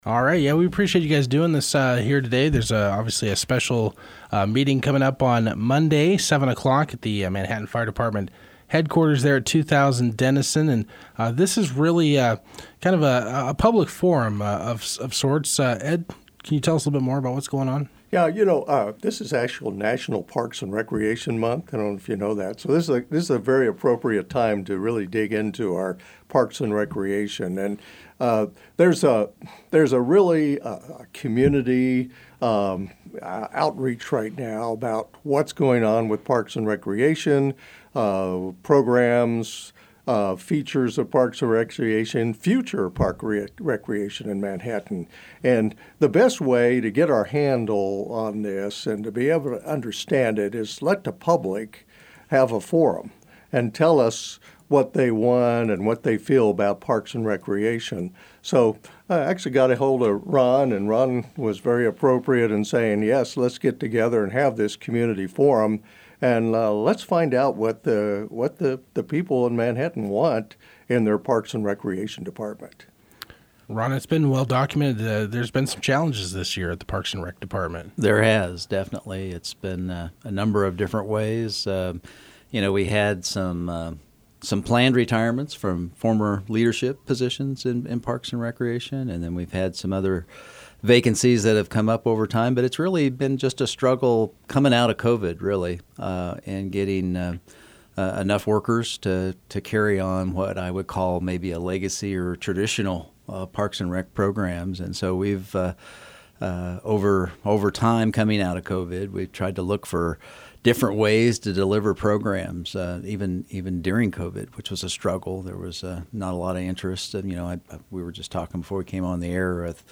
7-15-PR-Interview.mp3